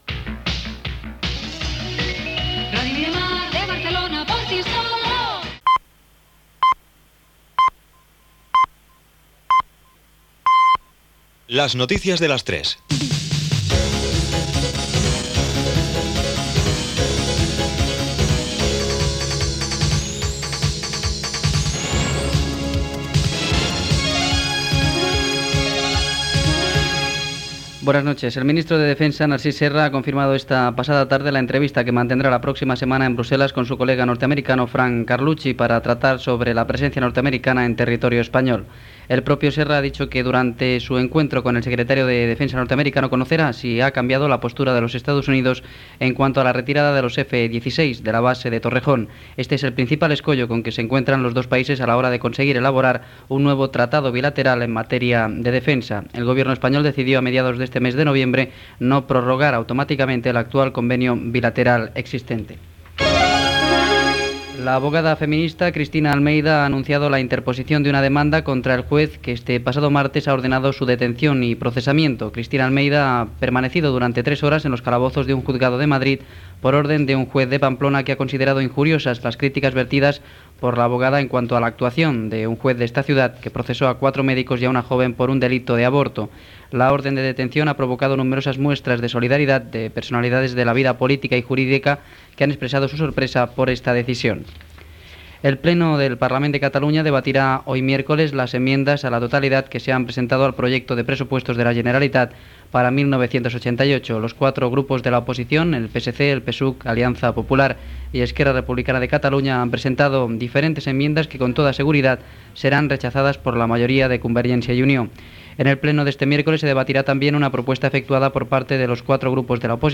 Indicatiu de l'emissora, careta del programa. Informació sobre el ministre de defensa Narcís Serra, la detenció de l'advocada Cristina Almeida, les esmenes als pressupostos del govern al Ple del Parlament, la visita dels reis d'Espanya a l'Àsia, el número de l'ONCE.
Informatiu